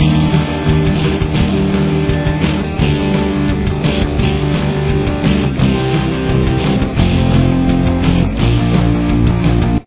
Chorus
Guitar 1
Guitar 2